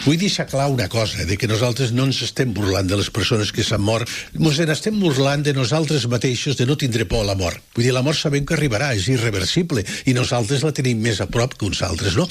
en una entrevista al magazine a l’FM i +